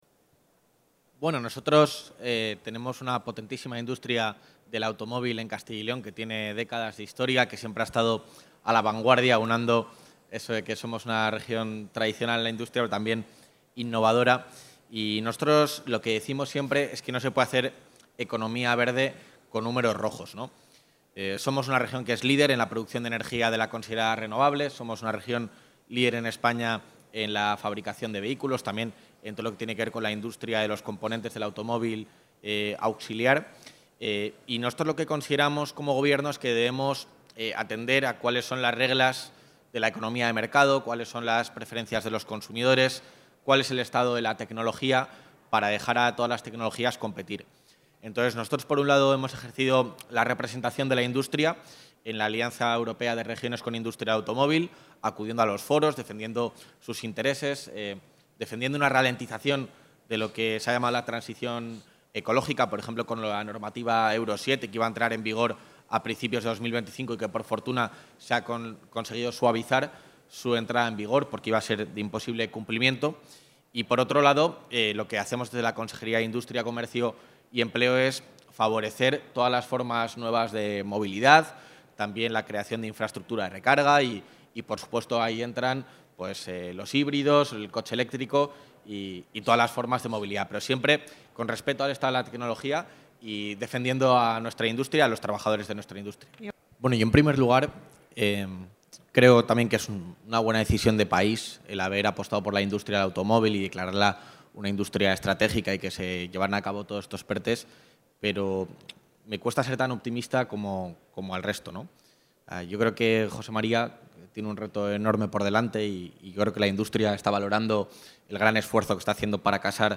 Intervención del vicepresidente de la Junta.
El vicepresidente de la Junta de Castilla y León, Juan García-Gallardo, ha participado esta mañana en la feria de movilidad sostenible eMobility, en Valencia, donde ha pedido “prudencia” a la hora de imponer políticas a la industria del automóvil.